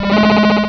sovereignx/sound/direct_sound_samples/cries/miltank.aif at master
miltank.aif